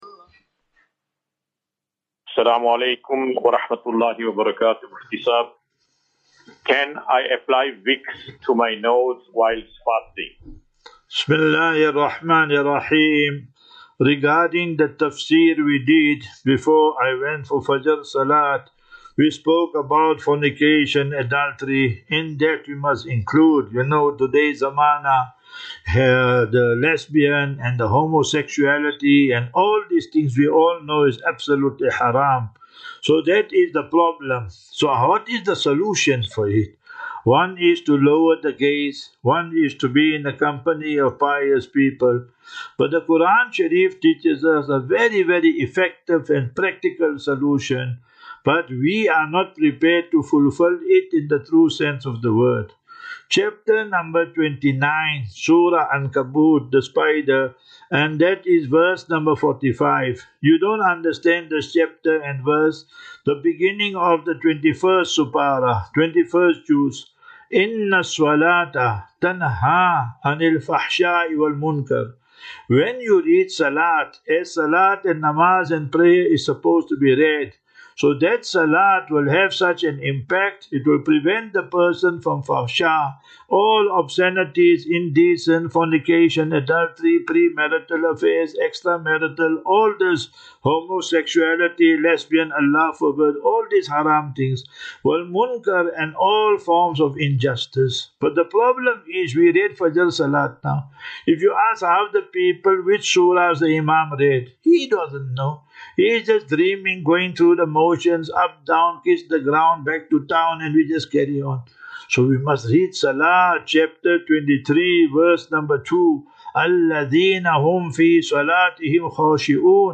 View Promo Continue Install As Safinatu Ilal Jannah Naseeha and Q and A 13 Mar 13 March 2025.